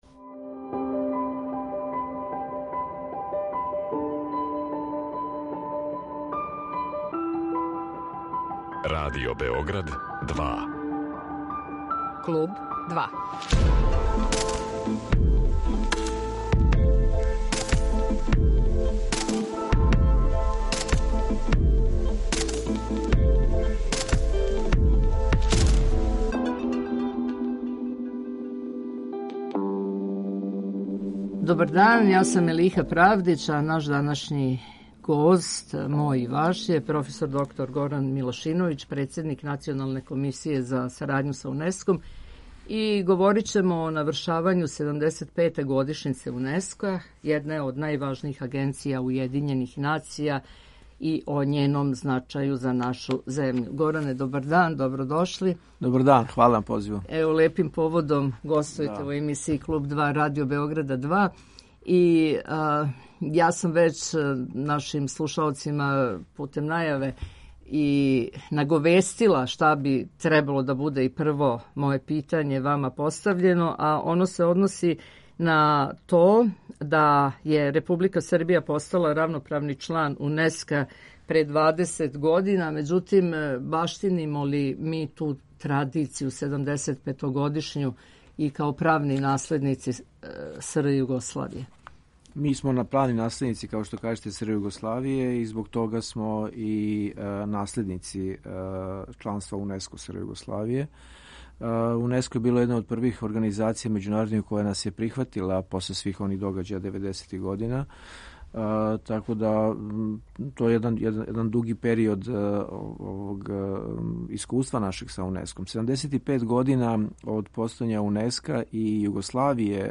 Гост Kлуба 2 је проф. др Горан Милашиновић, председник Националне комисије за сарадњу са Унеском, а говоримо о навршавању седамдесетпете годишњице Унеска, једне од најважнијих агенција Уједињених нација и њеном значају за нашу земљу.